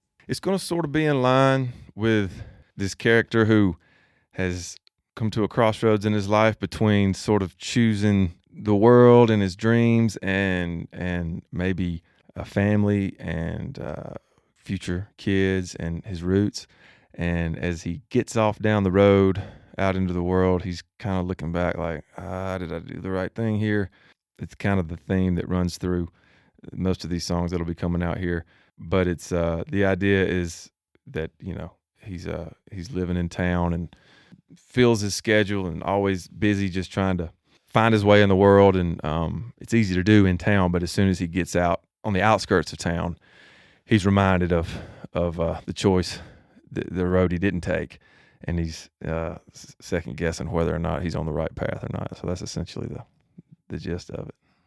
Audio / SAM HUNT TALKS ABOUT HIS SONG "OUTSKIRTS."